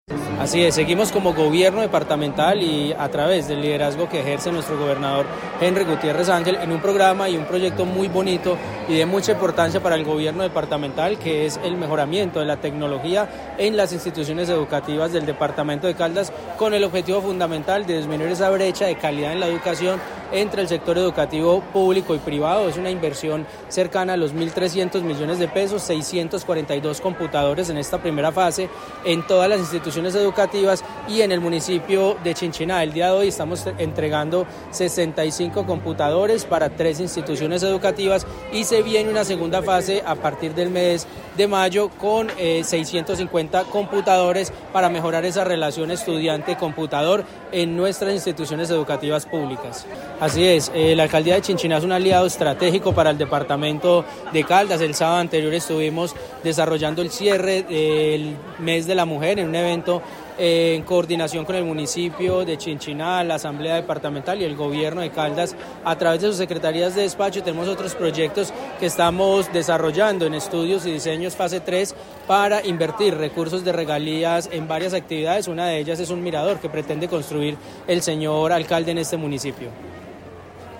El acto de entrega se realizó en compañía de la comunidad de la Institución Educativa San Francisco de Paula.
Gobernador encargado de Caldas, Ronald Bonilla.